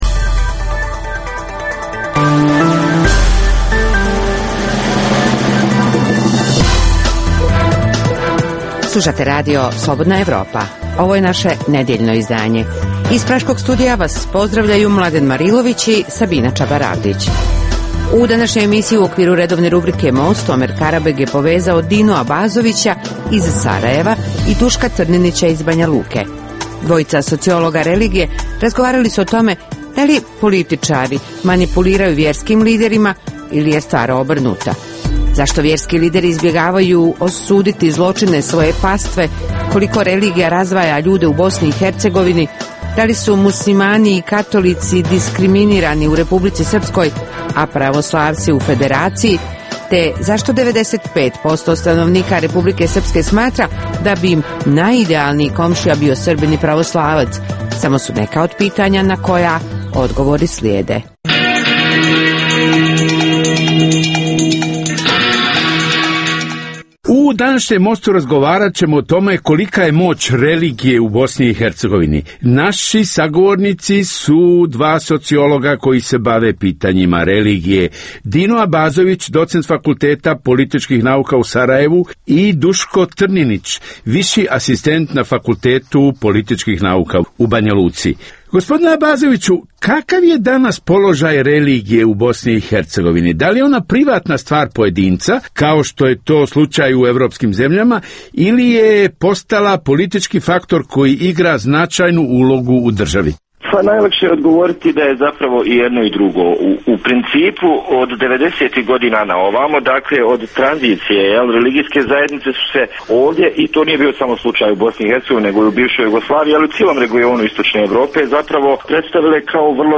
u kojem ugledni sagovornici iz regiona diskutuju o aktuelnim temama. Drugi dio emisije čini program "Pred licem pravde" o suđenjima za ratne zločine na prostoru bivše Jugoslavije.